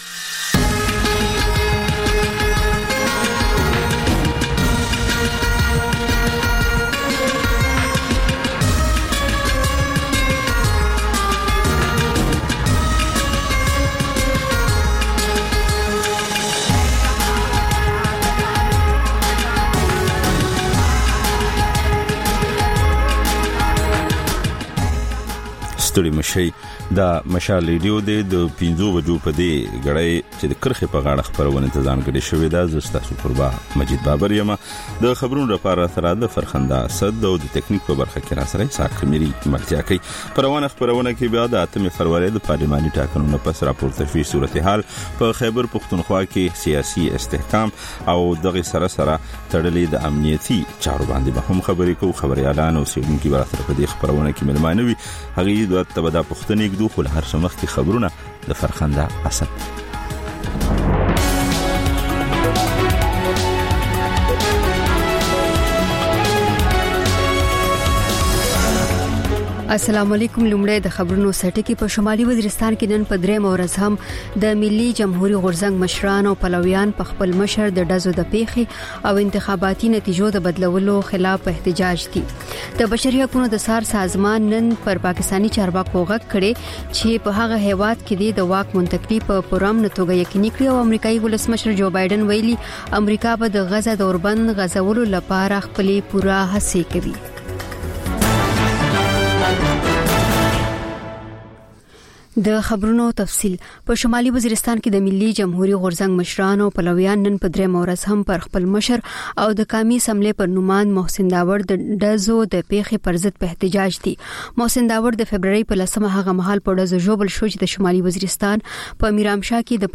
د مشال راډیو ماښامنۍ خپرونه. د خپرونې پیل له خبرونو کېږي، بیا ورپسې رپورټونه خپرېږي.
ځېنې ورځې دا ماښامنۍ خپرونه مو یوې ژوندۍ اوونیزې خپرونې ته ځانګړې کړې وي چې تر خبرونو سمدستي وروسته خپرېږي.